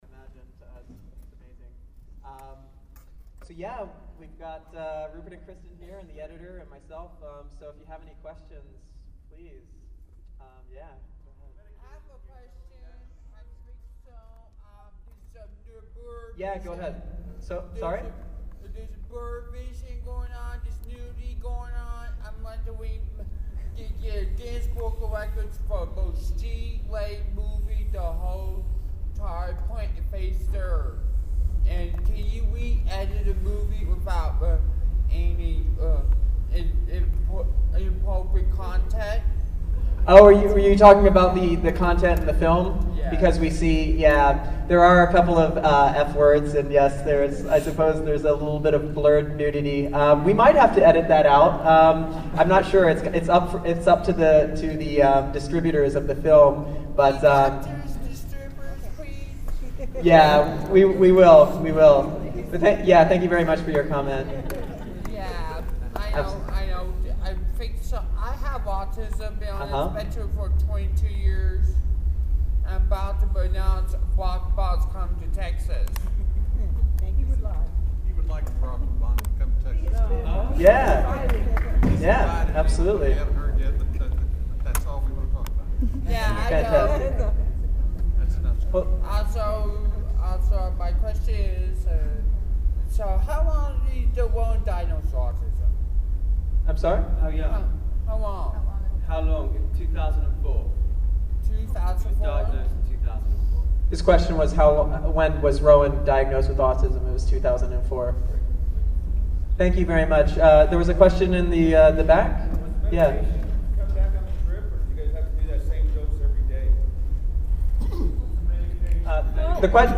I was happy to see this film while in Austin for the South by Southwest Film Festival because the family it’s about live in the hill country just outside of Austin, and my companion at the screening actually worked as the father’s masseuse for a while.
overthehills_qa.mp3